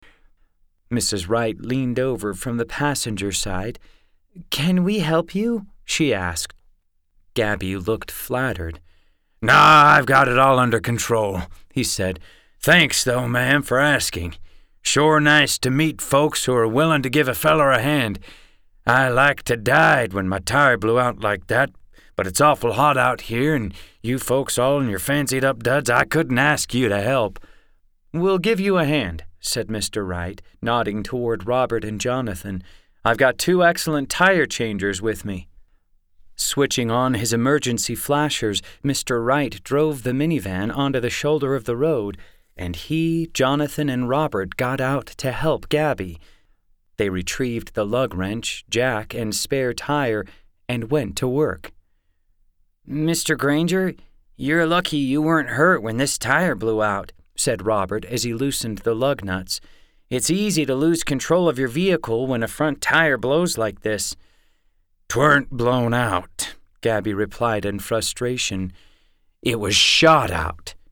Fun Audiobooks!